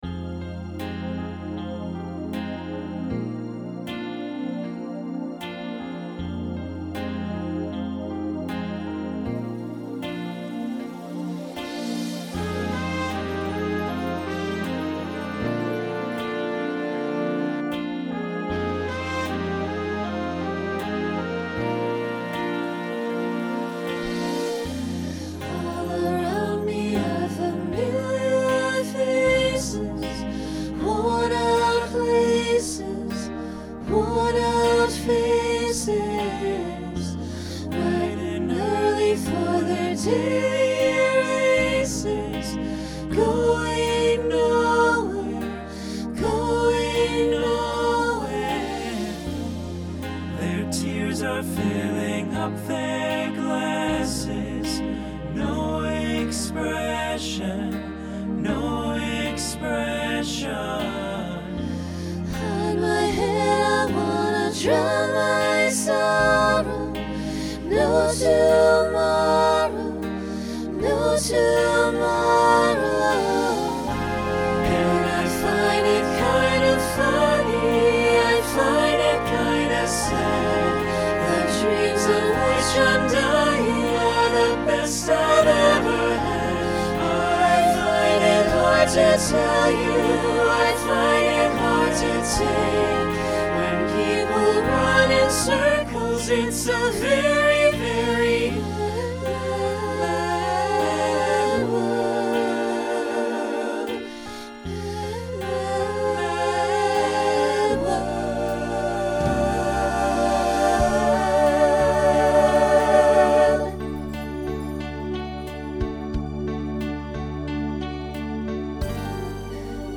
Genre Rock
Ballad Voicing SATB